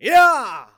xys长声3.wav 0:00.00 0:00.79 xys长声3.wav WAV · 68 KB · 單聲道 (1ch) 下载文件 本站所有音效均采用 CC0 授权 ，可免费用于商业与个人项目，无需署名。
人声采集素材